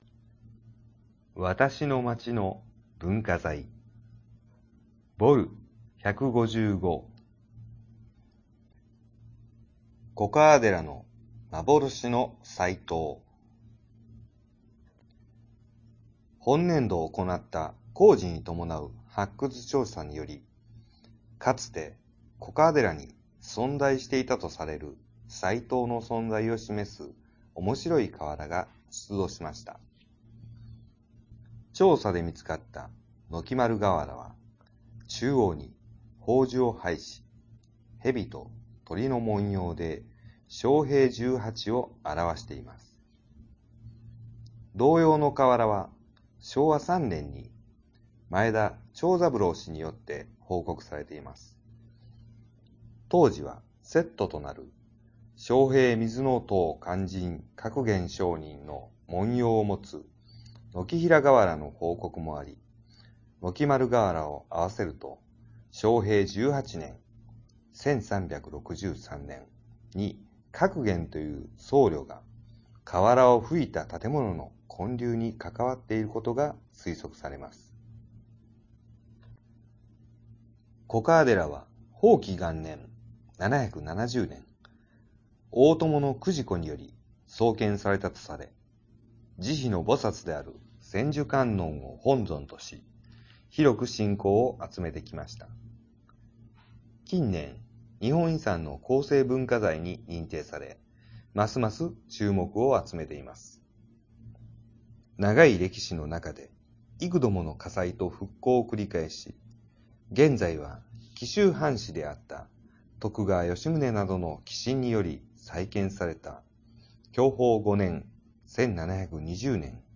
「広報紀の川」の音声版を、MP3形式の音声ファイルでダウンロードしていただけます。